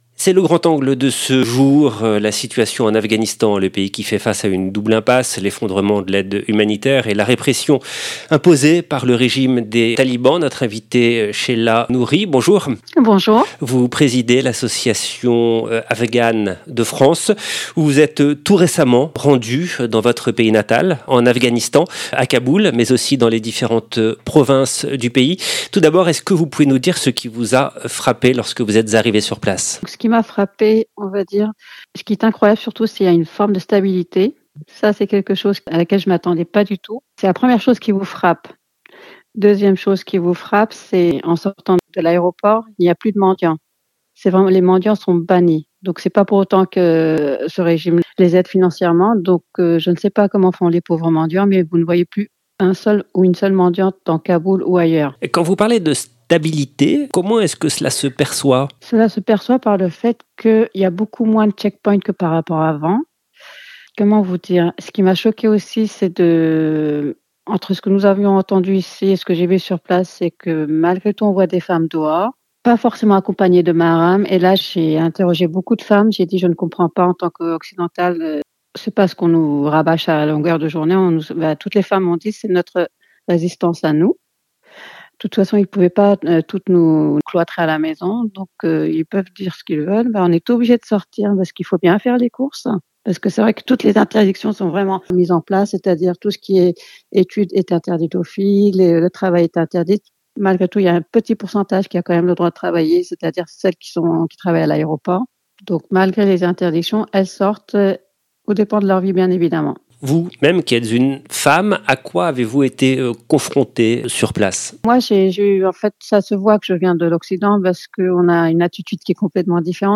Dans cet entretien, elle nous fait part de ce qu'elle a vu, elle évoque aussi la situation dramatique des femmes privées de liberté et évoque parallèlement une forme de « stabilité » dans le pays. 0:00 10 min 29 sec